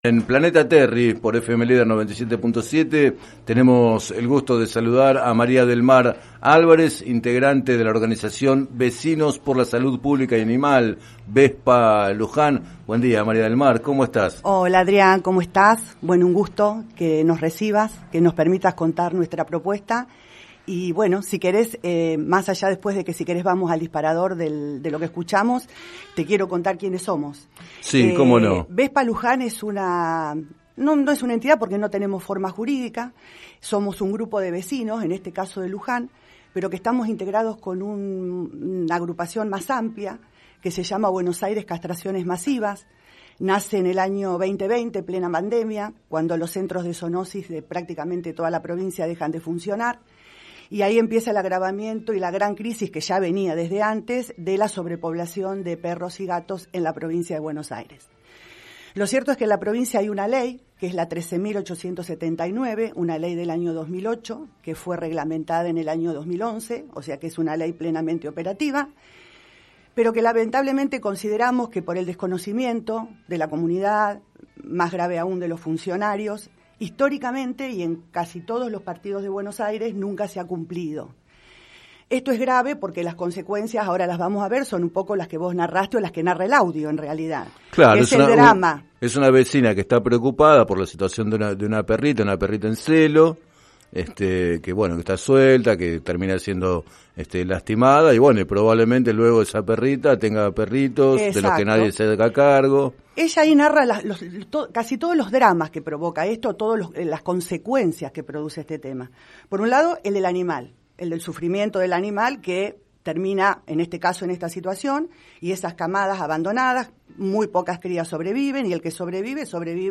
Entrevistada en el programa Planeta Terri de FM Líder 97.7